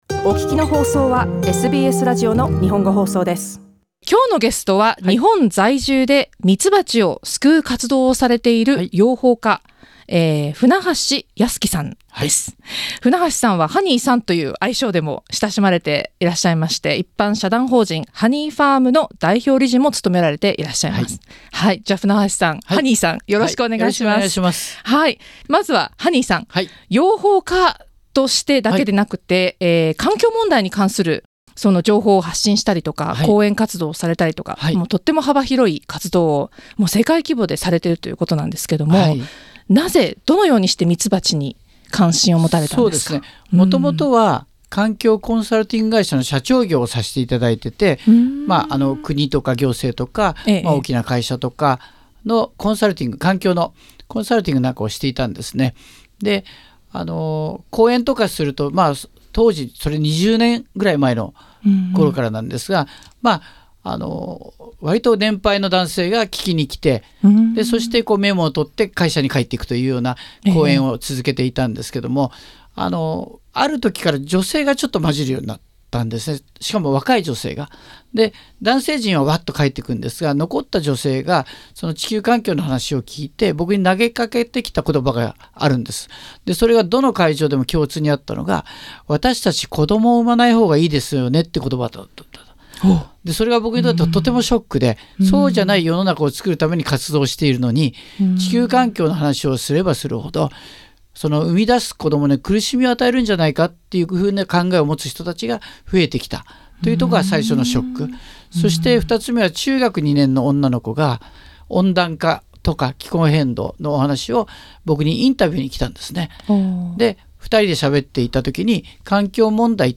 SBSのスタジオでお話を伺いました。